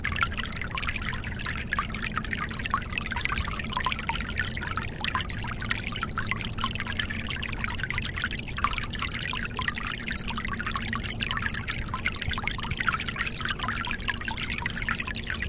潺潺溪流
描述：一条流动的小河
Tag: 森林